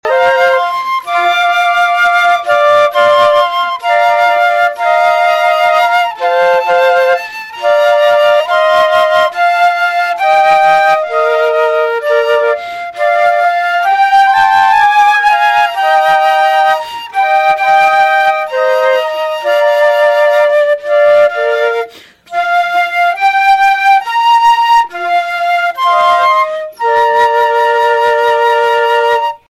In addition, there are several performances on the calendar — including one on KVOE’s Morning Show earlier this week.
2263-flute-choir-1.mp3